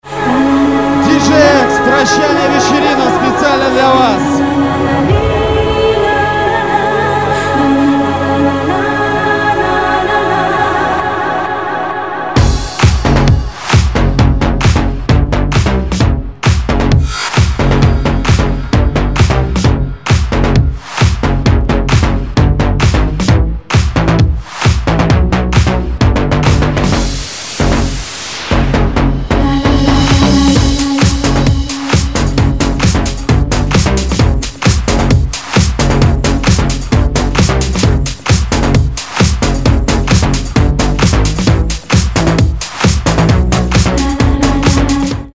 Назад в Мр3 | House
Жёсткий хаус, слушаем, комментируем...